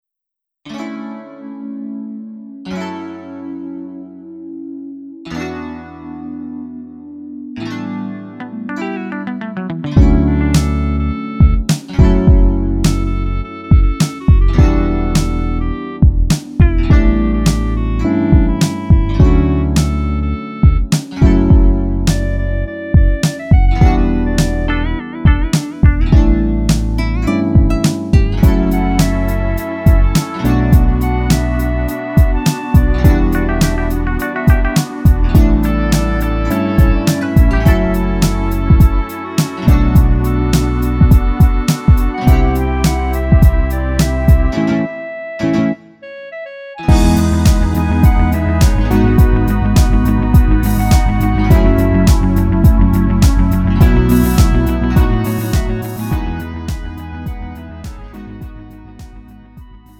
음정 원키 3:41
장르 구분 Lite MR